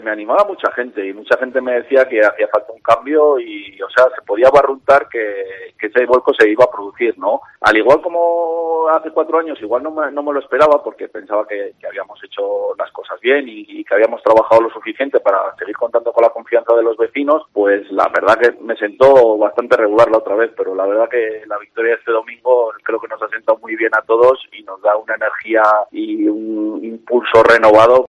En los micrófonos de COPE, Bengoa ha mostrado su satisfacción por los resultados y ha avanzado cuáles serán sus prioridades nada más recuperar el bastón de mando de Ezcaray